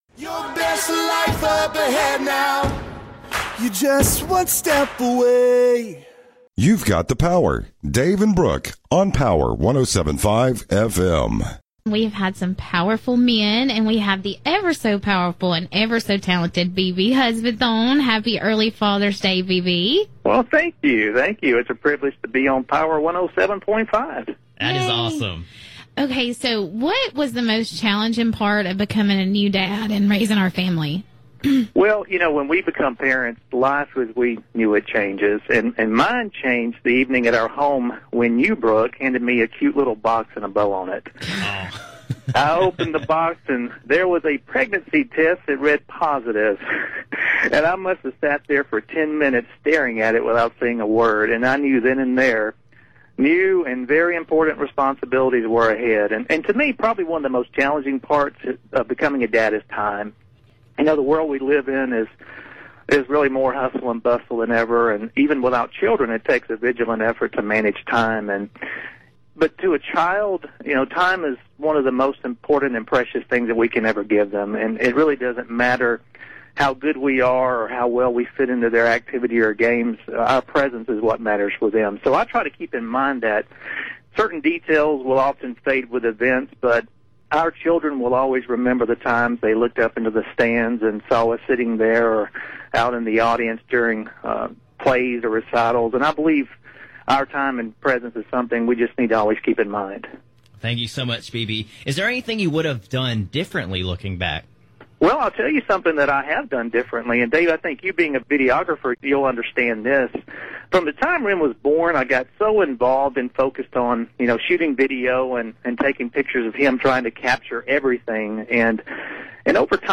Hear the interview and a visit from a “surprise celebrity guest” here: